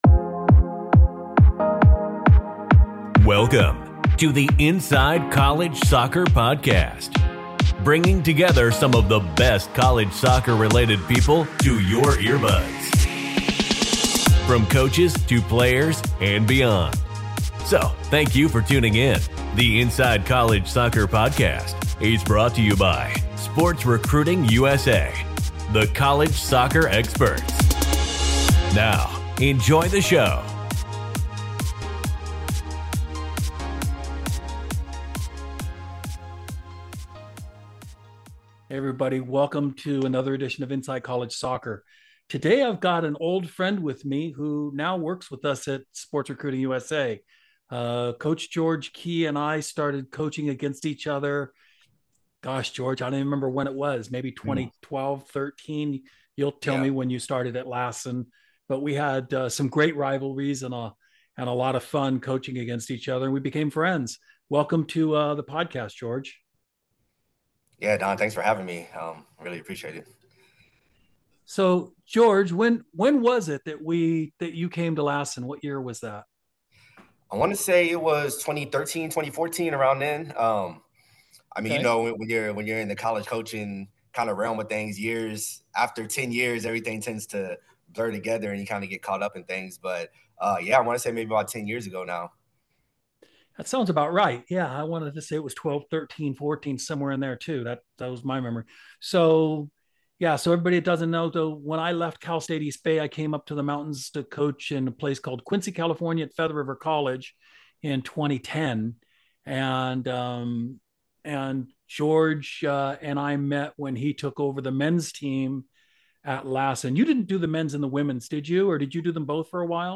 Podcast Call It What You Want McKennie's having fun at Juventus, Sargent's not having fun at Norwich, USMNT & MLS mailbag (Soccer 1/22) Weston McKennie is bossing the Champions League, and we’re here for it! Jimmy Conrad, Charlie Davies, and Tony Meola discuss the Juventus midfielder’s UCL goal streak as well as his comments about being willing to run through a wall for Luciano Spalletti (06:43).